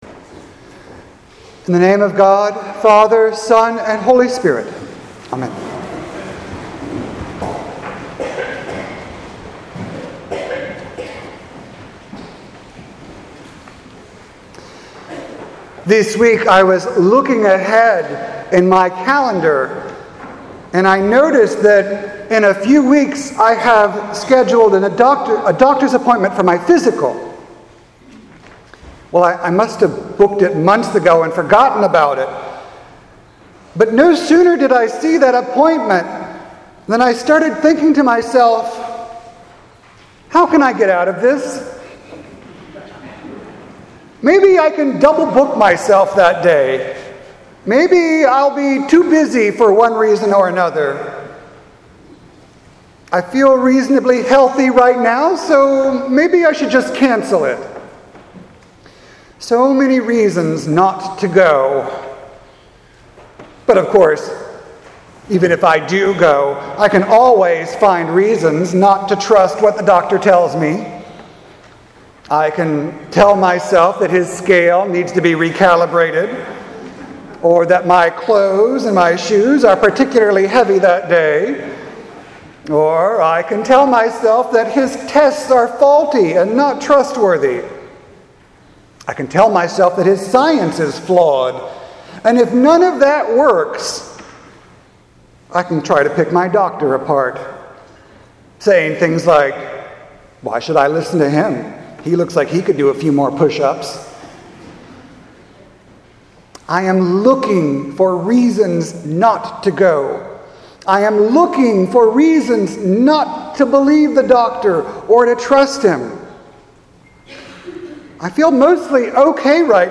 sermon-5-12-19.mp3